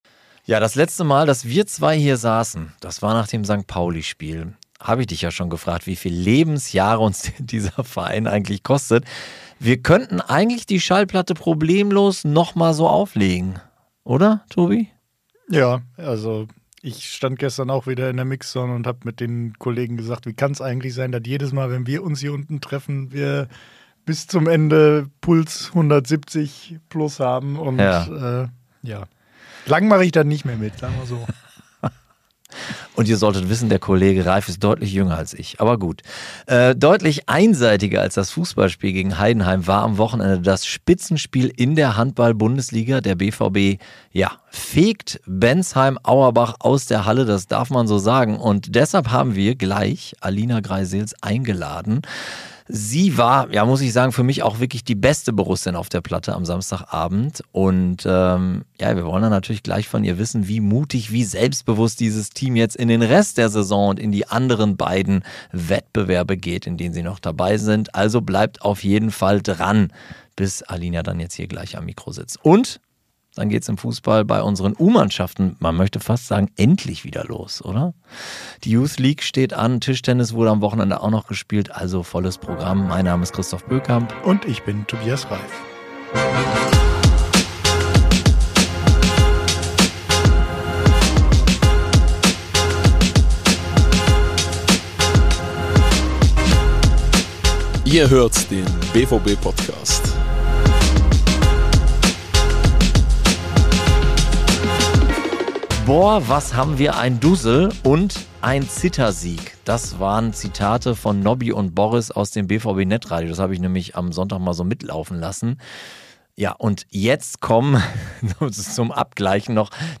Außerdem: alles zu unseren U-Mannschaften und eine Trikotverlosung live am Telefon.